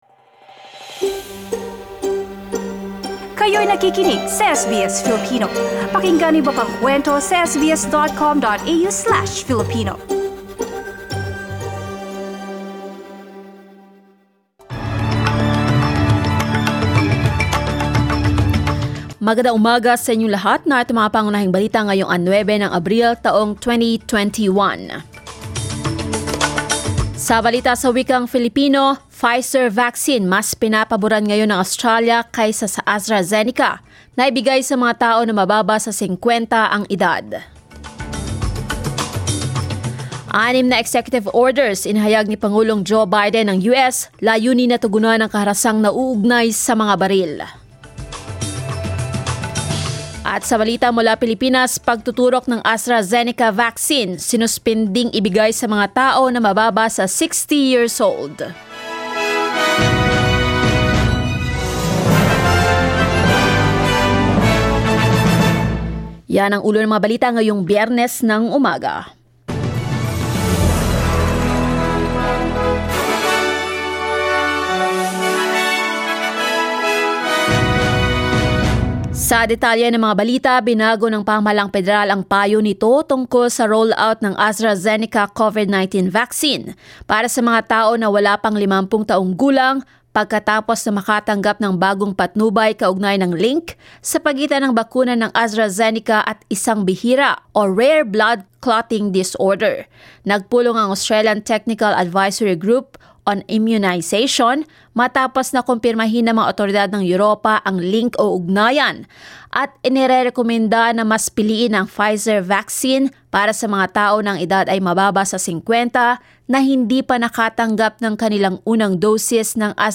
SBS News in Filipino, Friday 9 April